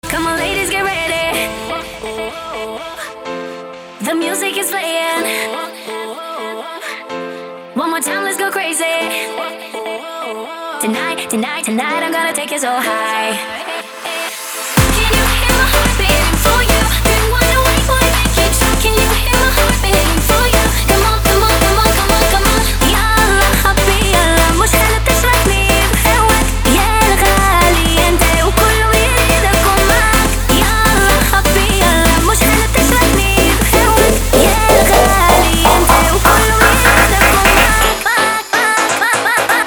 • Качество: 320, Stereo
красивые
веселые
восточные
арабские